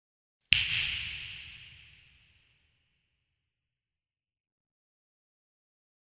今回はプリセットのLarge Hall Brightというものをベースに画像のような設定にしております。
フィンガースナップ（リバーブ有）